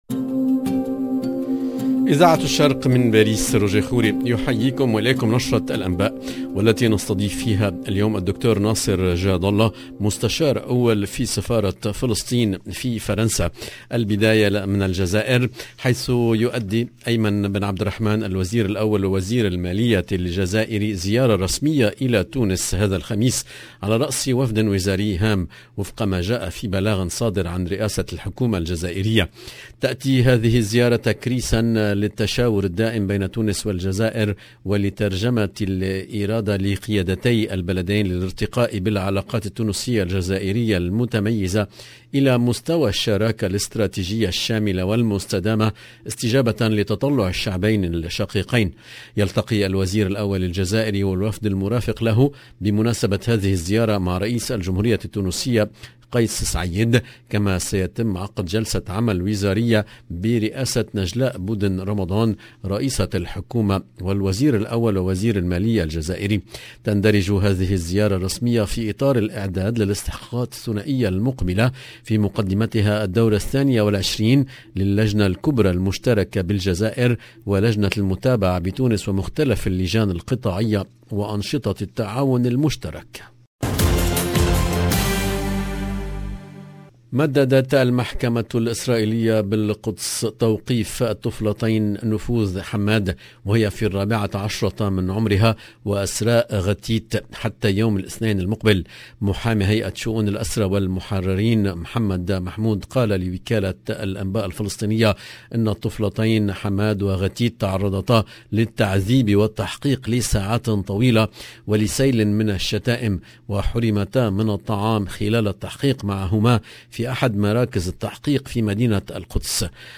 LE JOURNAL DU SOIR EN LANGUE ARABE DU 9/12/21